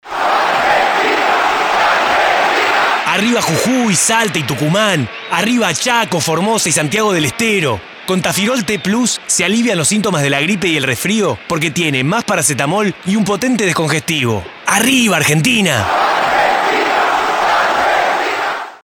Otro que quiso estar cerca del mundial fue el nacional Sidus, que con una campaña radial ha puesto de relieve a su producto Tafirol T Plus.